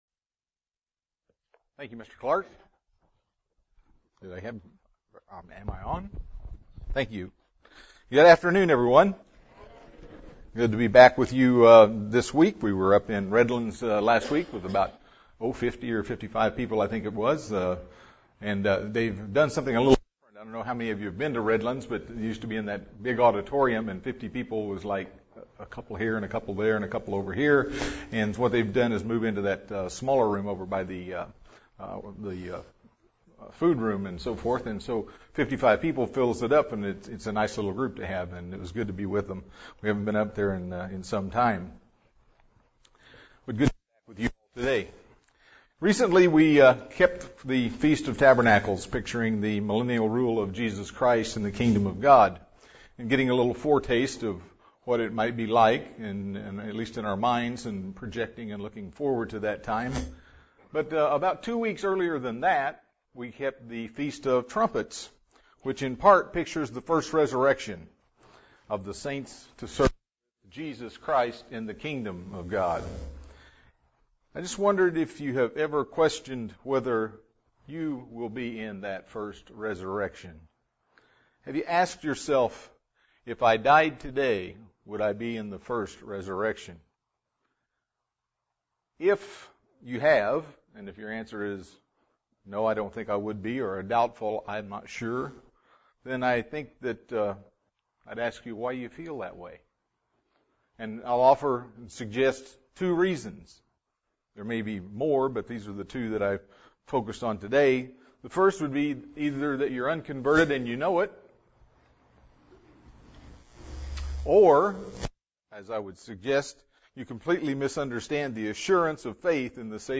UCG Sermon Studying the bible?
Given in San Diego, CA